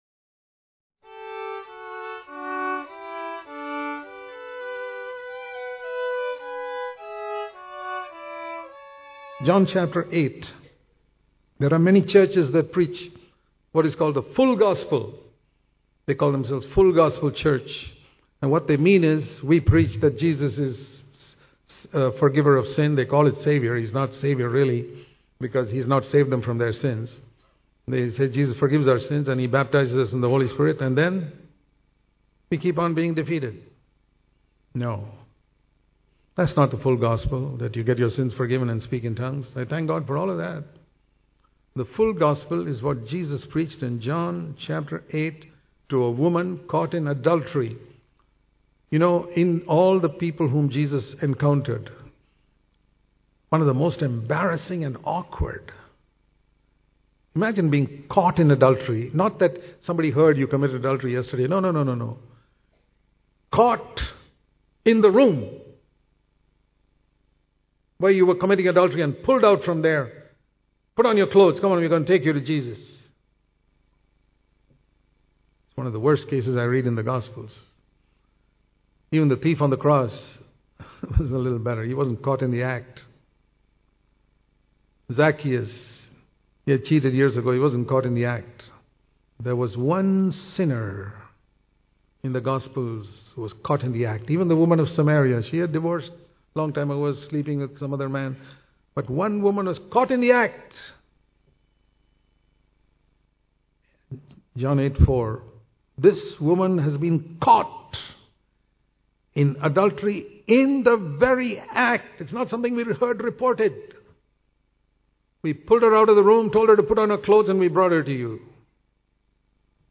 Daily Devotion